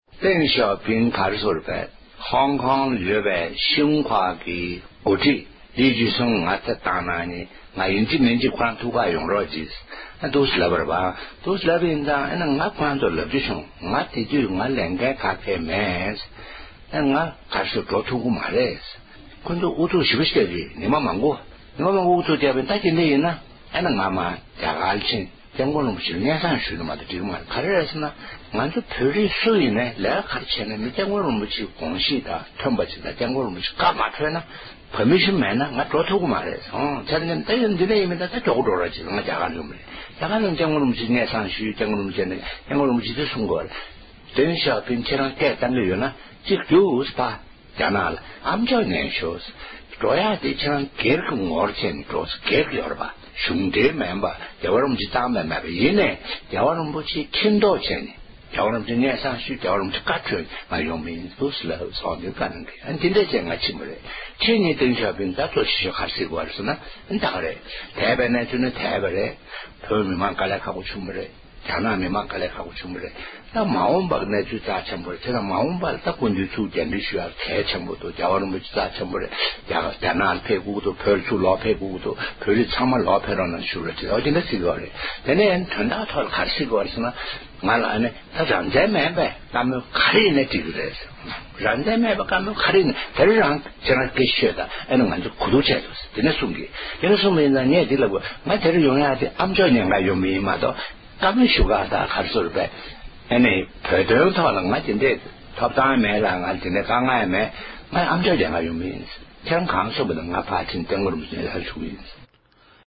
རང་བཙན་མ་གཏོགས་གང་ཡང་གླེང་ཆོག སྐུའི་གཅེན་པོ་བཀའ་ཟུར་རྒྱ་ལོ་དོན་གྲུབ་མཆོག་ནས་བཞུགས་སྒར་དུ་གསར་འགོད་ལྷན་ཚོགས་ཤིག་གི་ཐོག་བཅའ་འདྲི་དང་ལེན་གནང་བཞིན་པ།